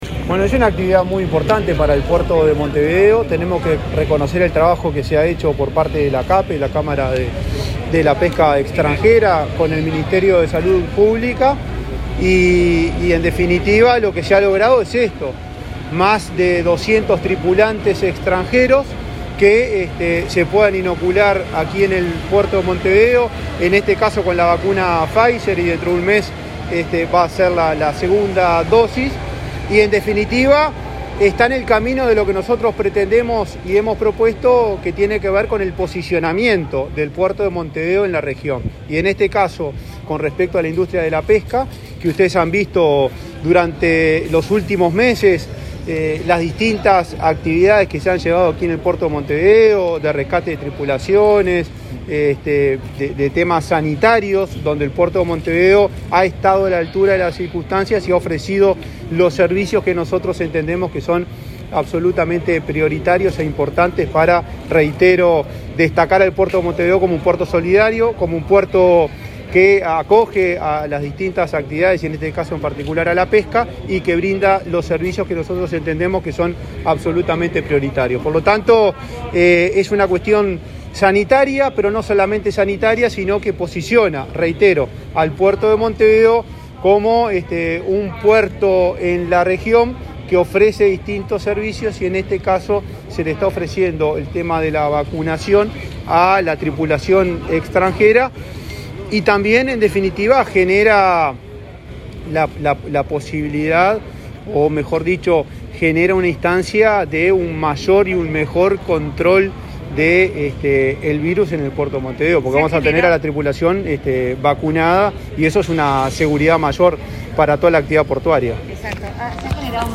Declaraciones a la prensa del presidente de la ANP, Juan Curbelo